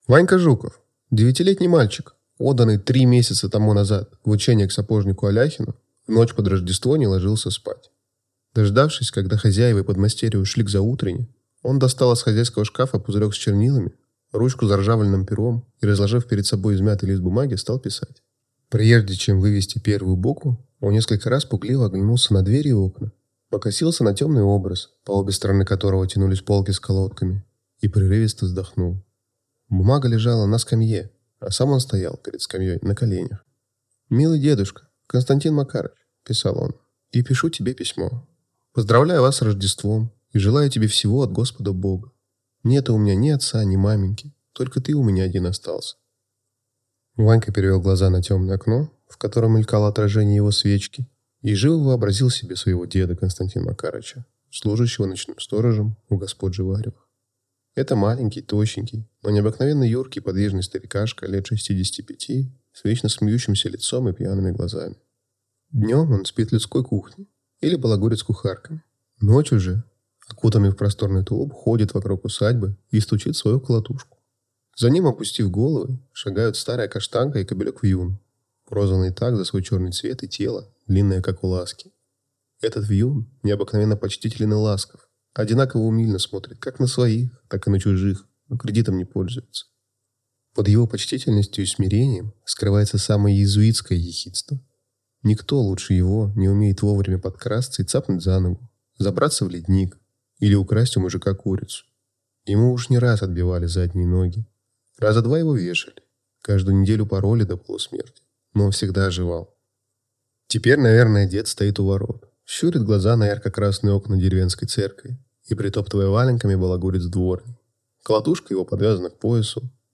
Аудиокнига Ванька | Библиотека аудиокниг